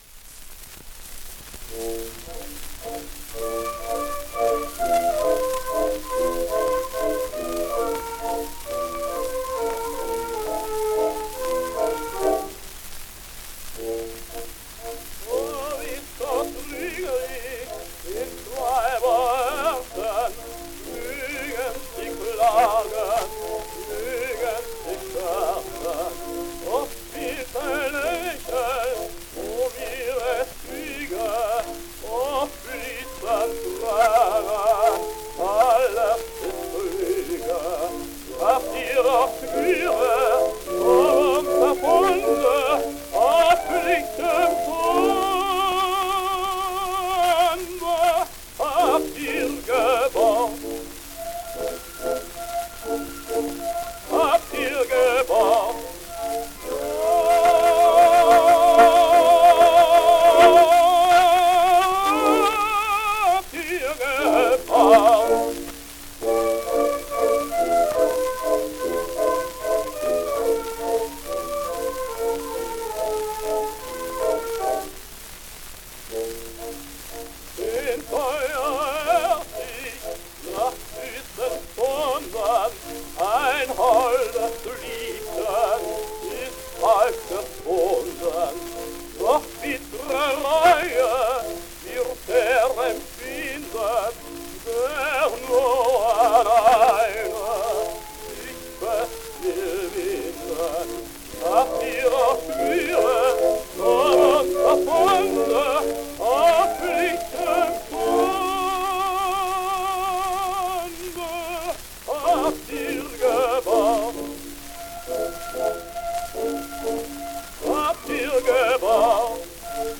A mystery tenor.